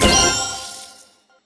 get_powerpoints_01v2.wav